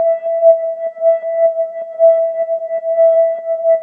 cch_synth_loop_room_125_Em.wav